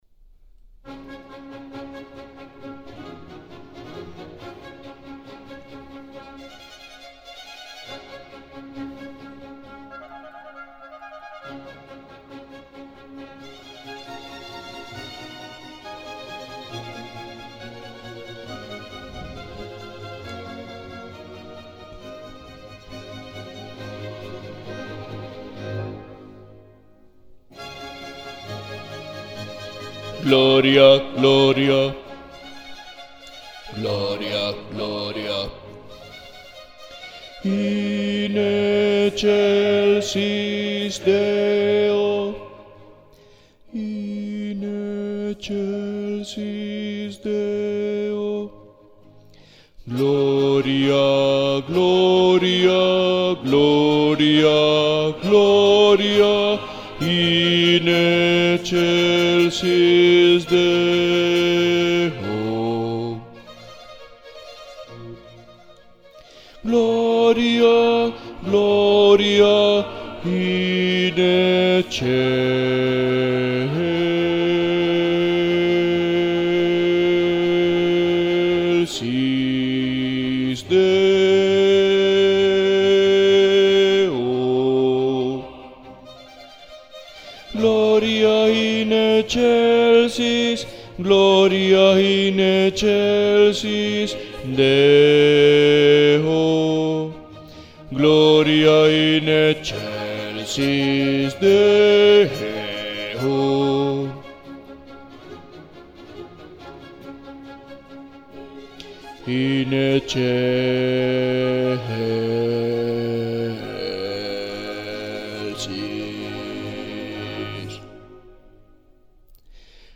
Musica SACRA Bajos